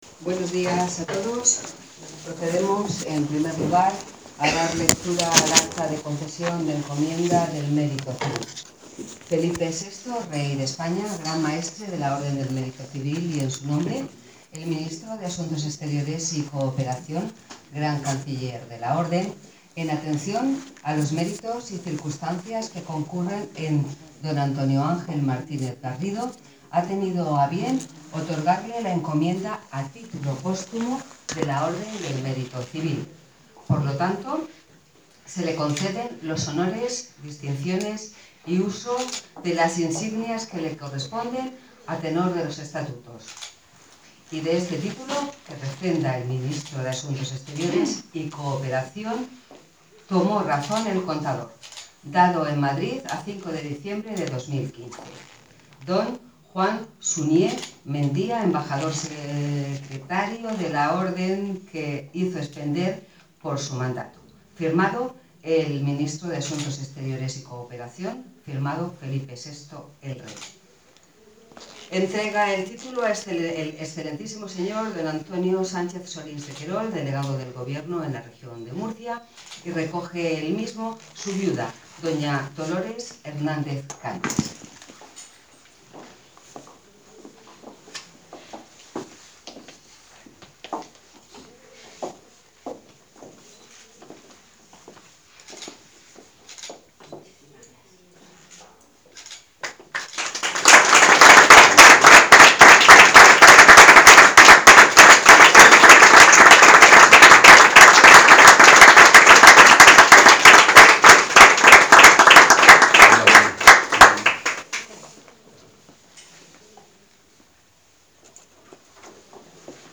en un acto que se celebró en el salón de actos del edificio de Servicios Múltiples de la Delegación del Gobierno de Murcia.